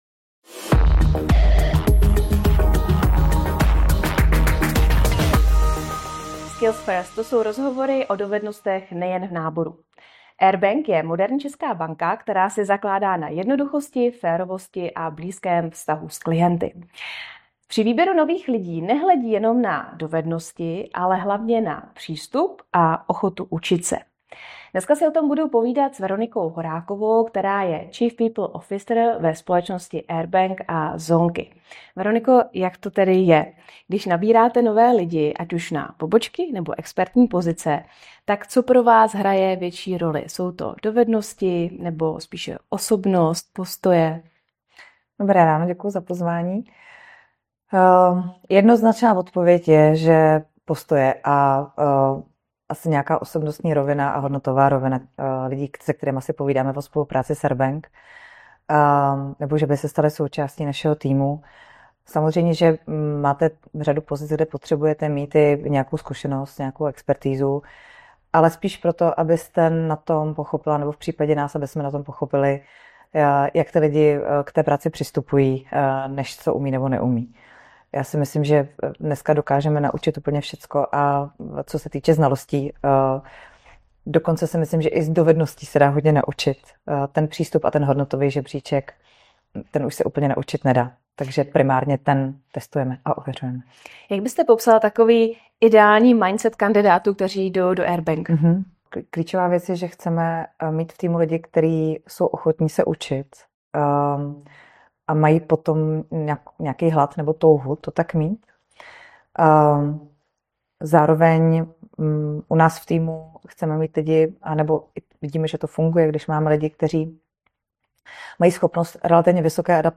Celý rozhovor si můžete poslechnout i na našem YouTube kanálu: